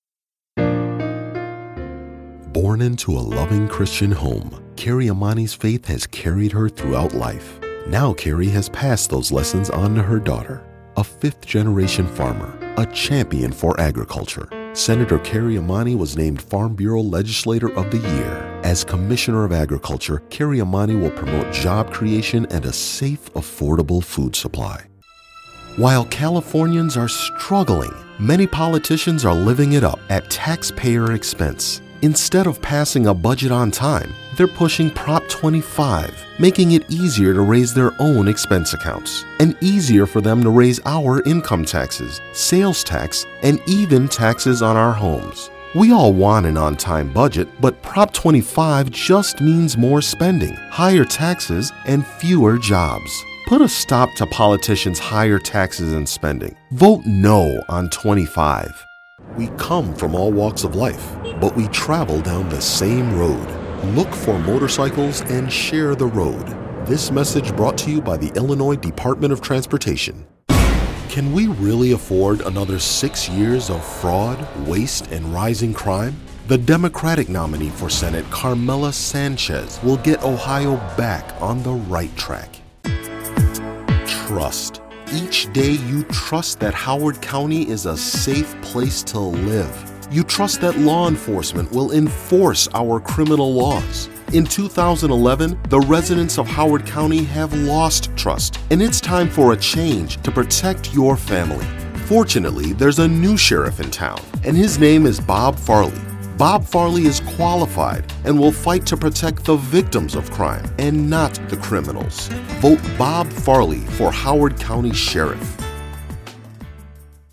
English - USA and Canada
Middle Aged